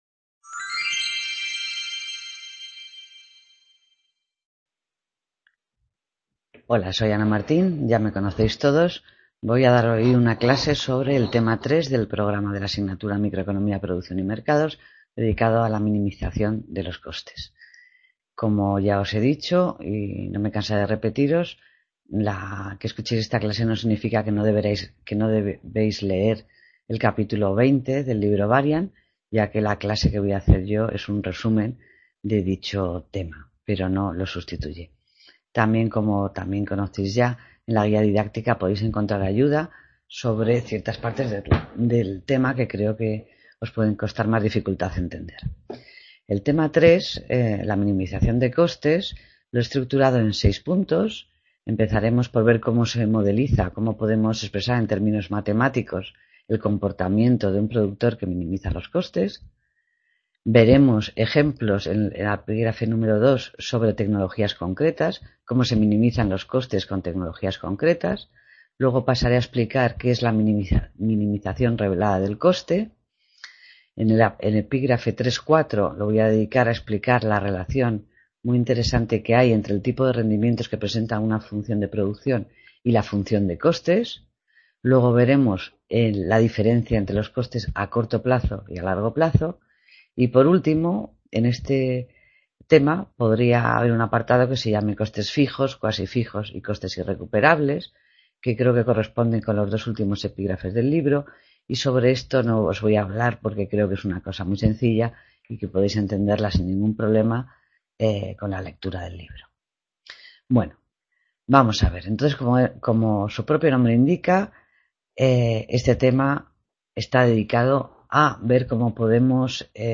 Clase